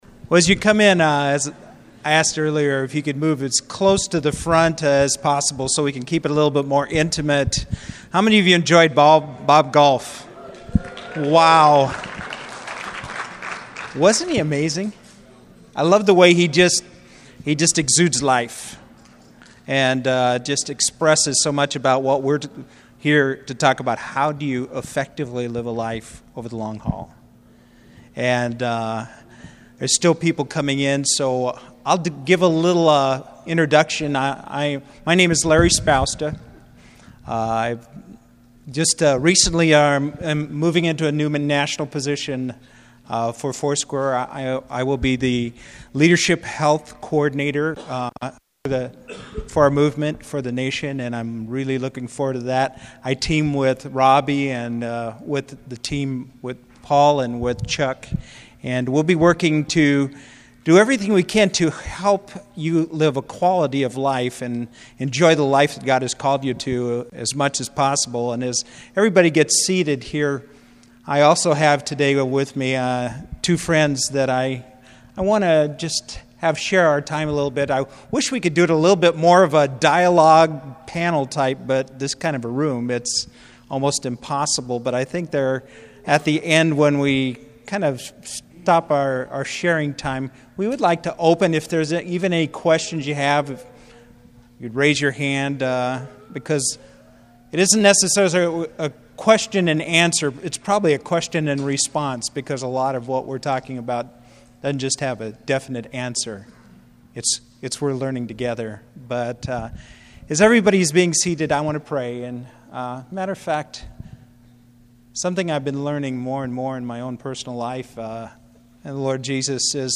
In this Connection Studio session, recorded live at Foursquare Connection 2015 in Anaheim, Calif., you can listen to an important discussion on this topic as leaders talk through the three keys to lifelong, effective ministry: solitude, Sabbath and sustainability.